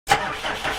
Tractr Ignition Single Shot
SFX
yt_qKTUBOJ-rFo_tractr_ignition_single_shot.mp3